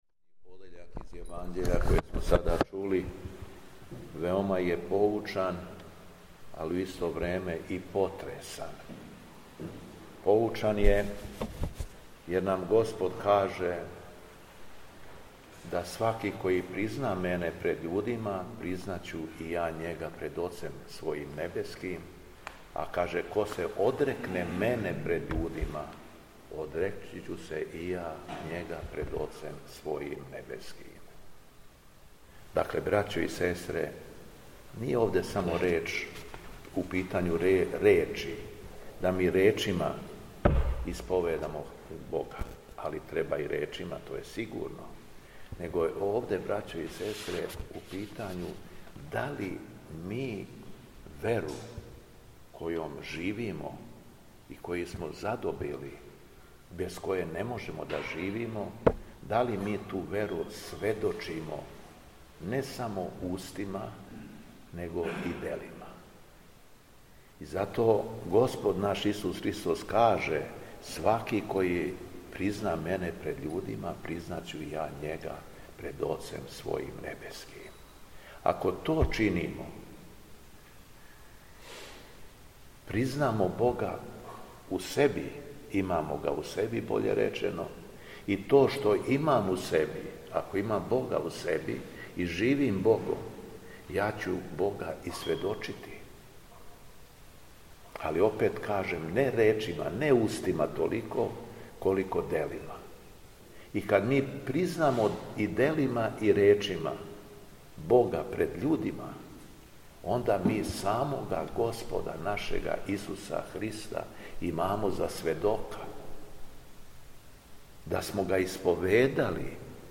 Беседа Његовог Високопреосвештенства Митрополита шумадијског г. Јована
Након прочитаног зачала јеванђеља по Матеју архијереј се обратиo поучном беседом: